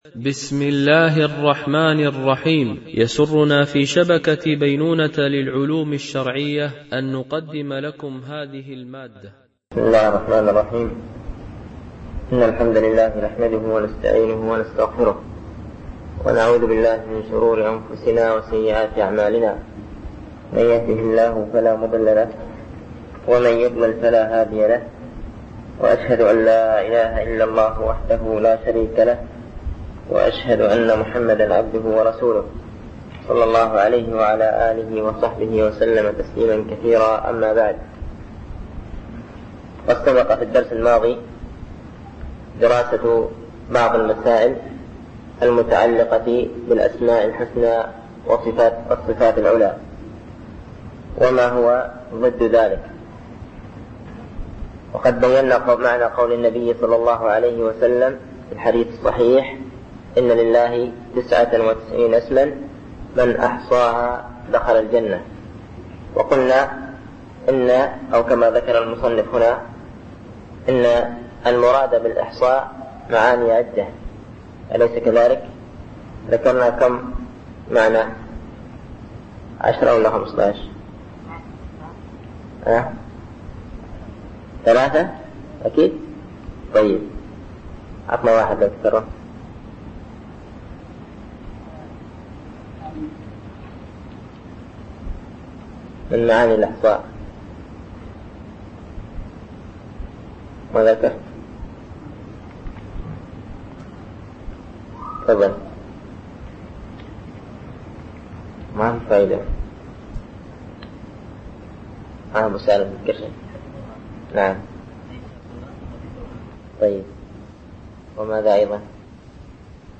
الألبوم: شبكة بينونة للعلوم الشرعية التتبع: 31 المدة: 21:07 دقائق (4.87 م.بايت) التنسيق: MP3 Mono 22kHz 32Kbps (CBR)